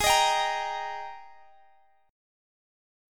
AbM7sus2 Chord
Listen to AbM7sus2 strummed